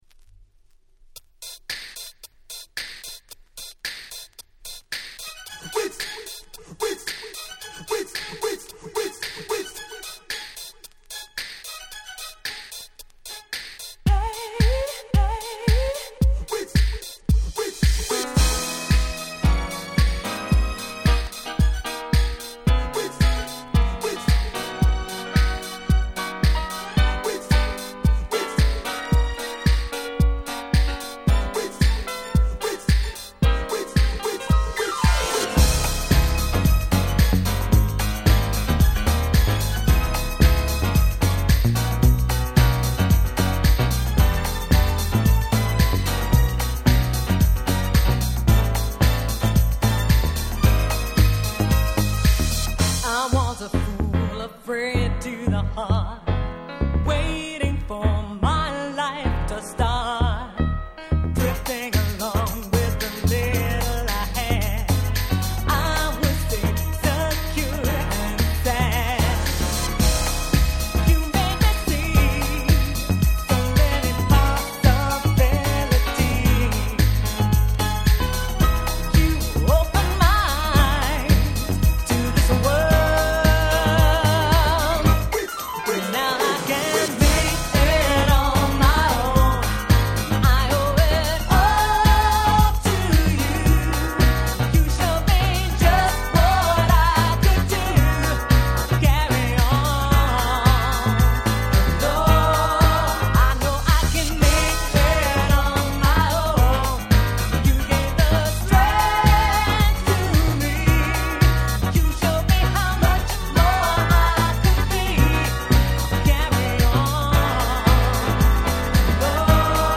House的なノリをもカバーした